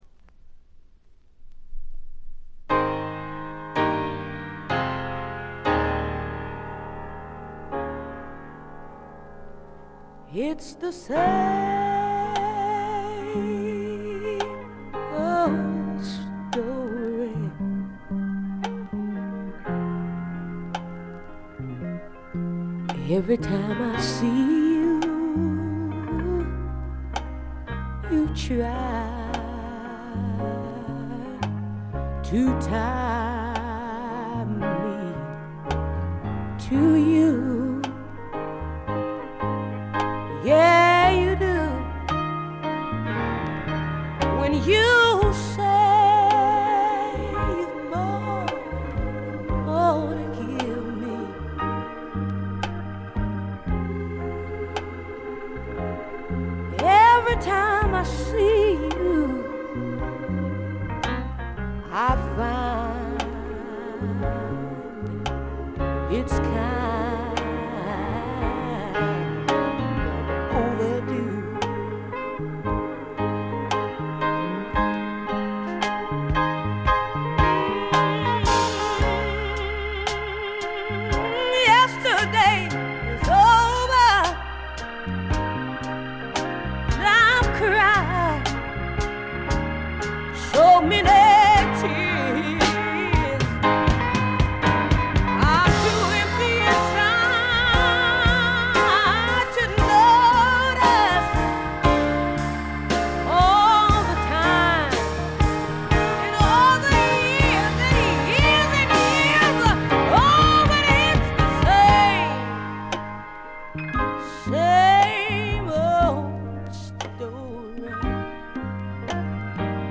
試聴曲は現品からの取り込み音源です。
Recorded At - A&M Studios